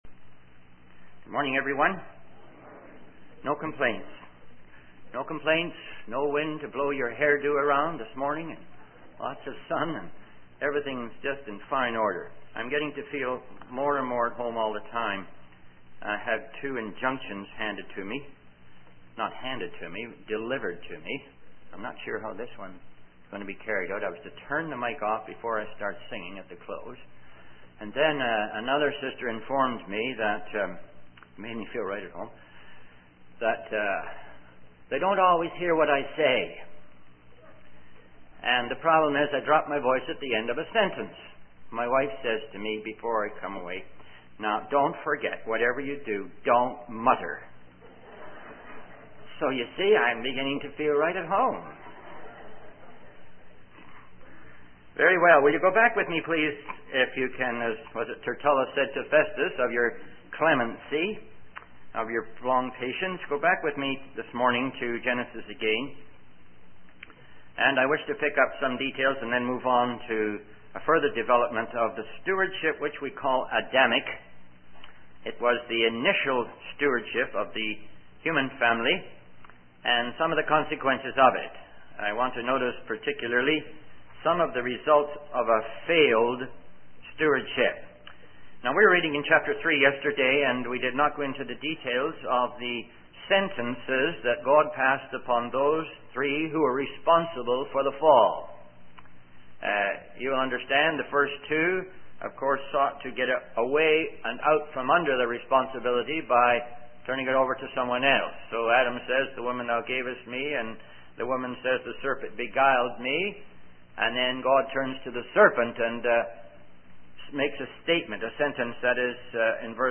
The sermon emphasizes the importance of recognizing our fallen nature and the need to turn to God for redemption.